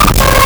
Player_Glitch [5].wav